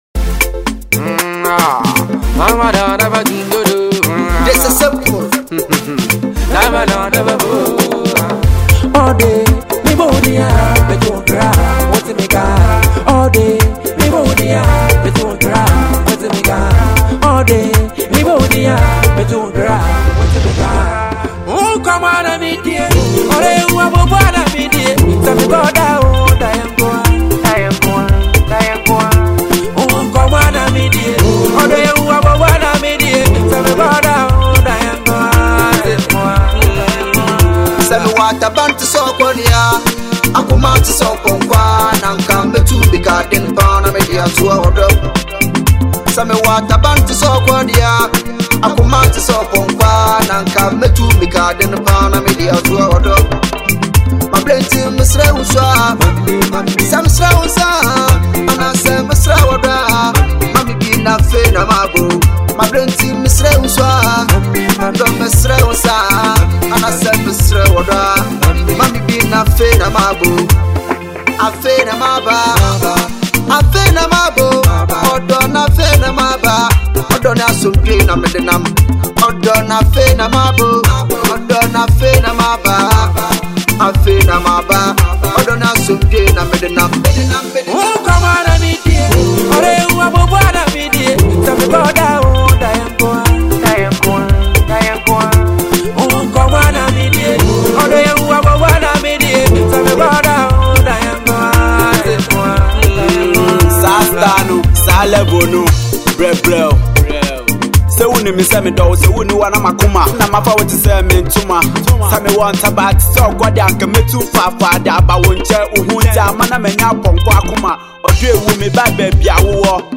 Ghana Music
love tune with Highlife feel